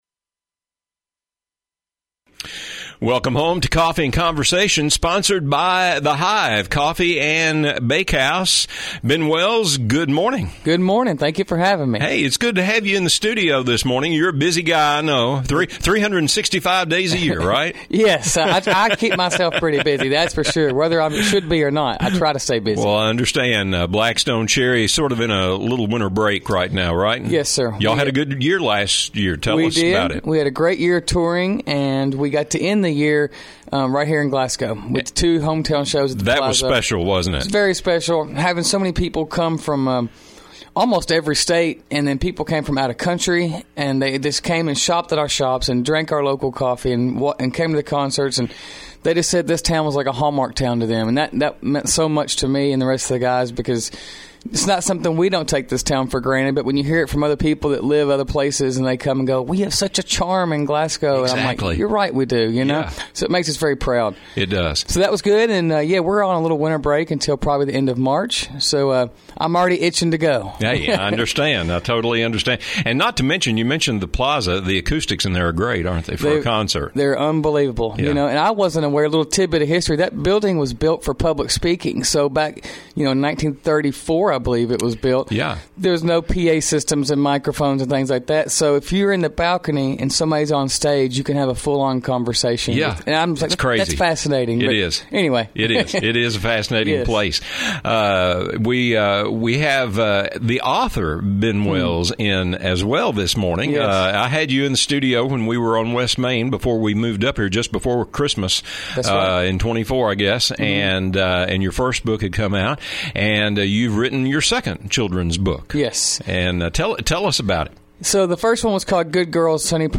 Ben Wells, guitarist for Black Stone Cherry and author/illustrator, recently visited WCLU Radio to talk about performing hometown shows and releasing his second children’s book.